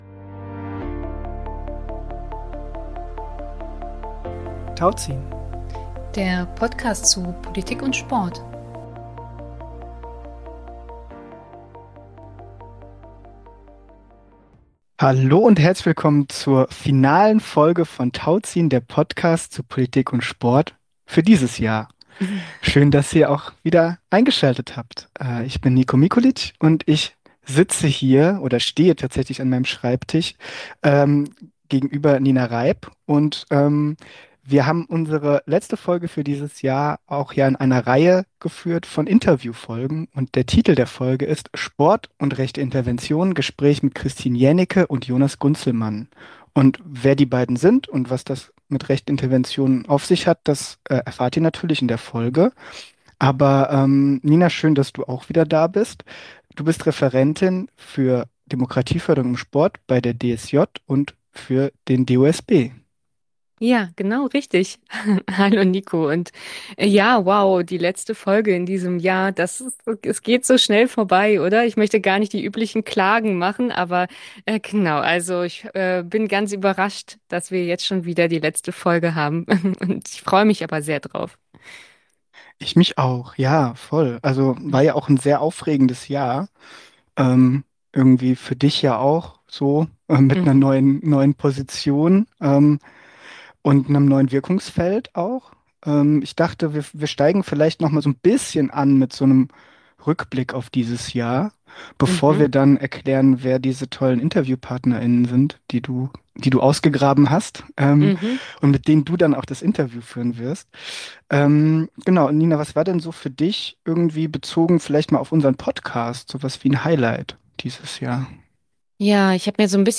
Sport und rechte Interventionen - Gespräch